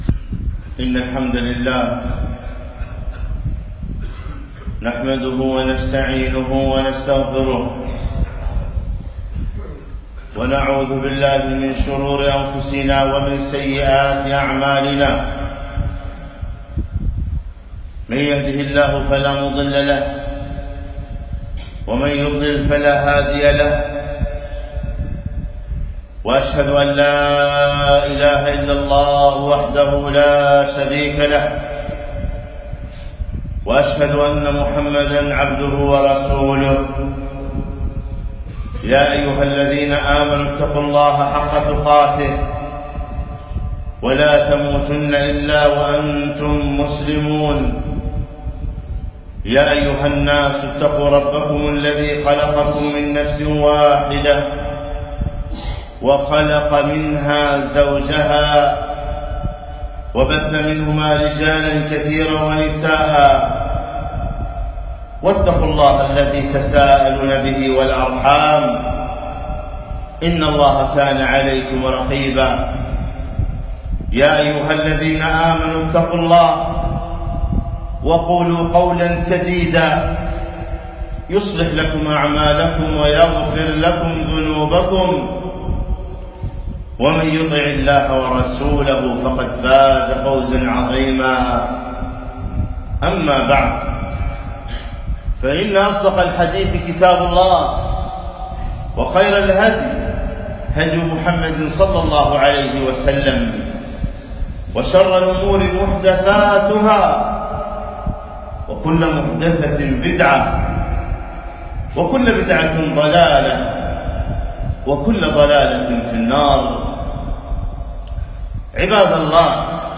خطبة - شهر الله المحرم فضائل وعبر